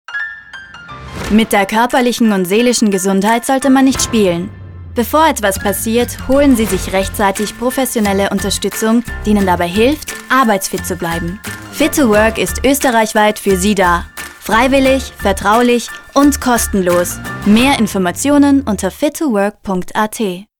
Hablante nativo
austríaco